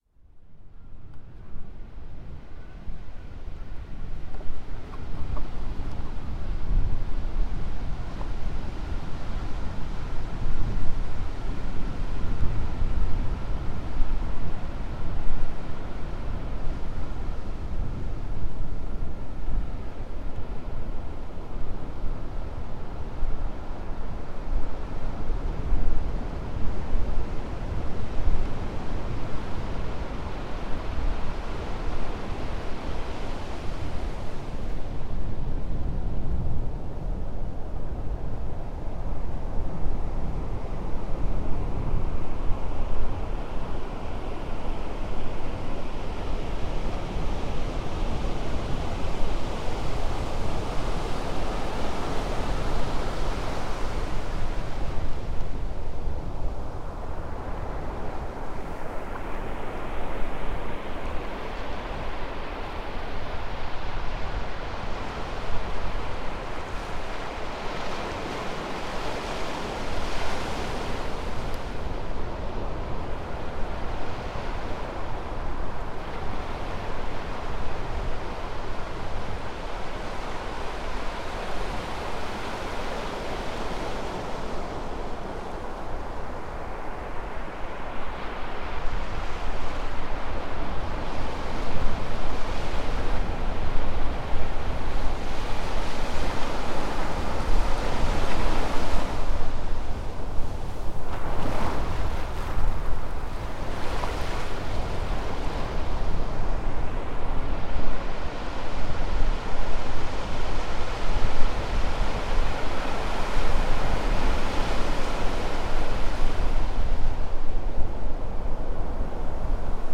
Mar en Cabeza de Toro, Chiapas.
La presente grabación es un paisaje sonoro de este mítico poblado Cabeza de Toro, recomendamos el uso de audífonos para una mejor experiencia sonora.